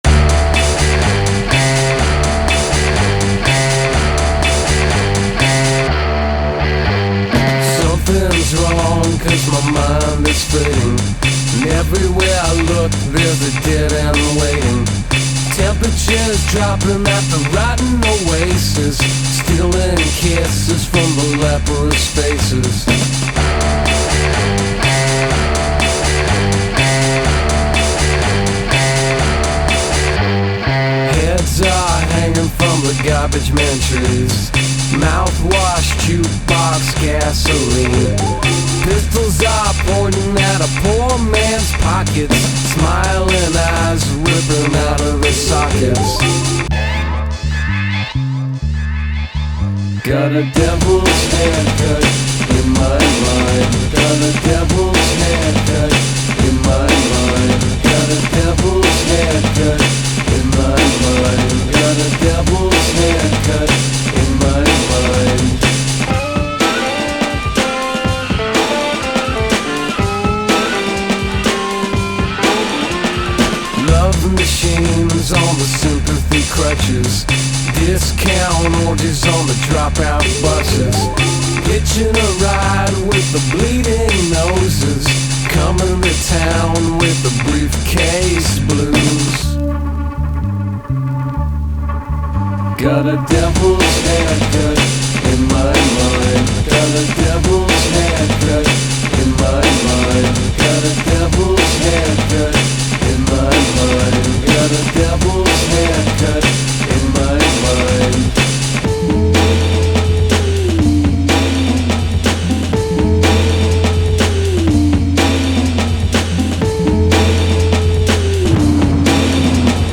vibrant